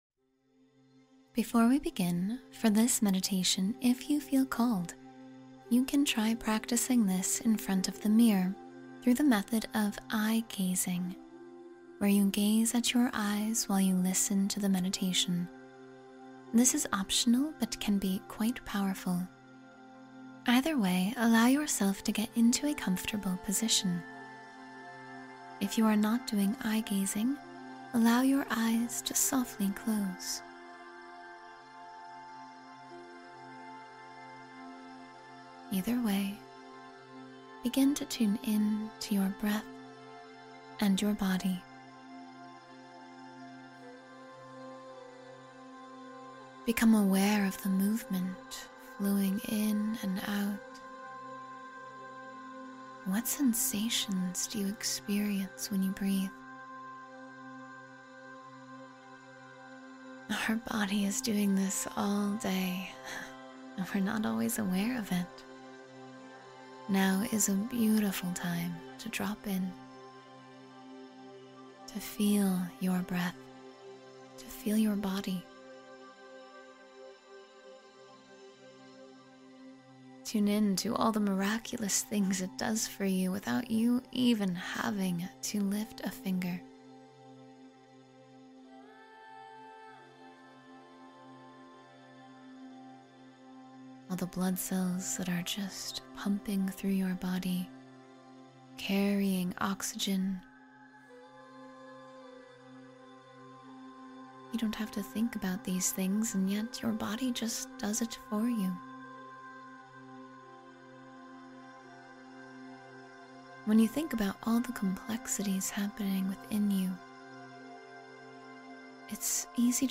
Self-Love Meditation for Daily Renewal and Inner Peace